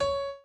b_pianochord_v100l4o6cp.ogg